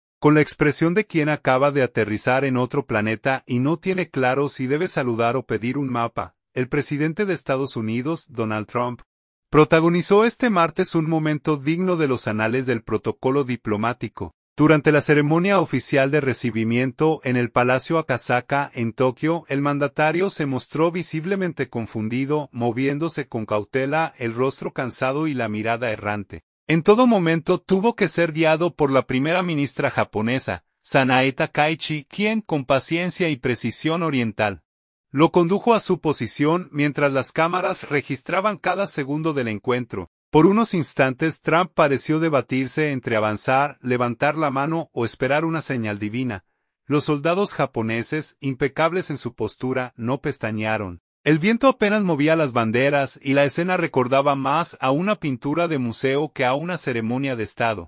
Durante la ceremonia oficial de recibimiento en el Palacio Akasaka, en Tokio, el mandatario se mostró visiblemente confundido, moviéndose con cautela, el rostro cansado y la mirada errante.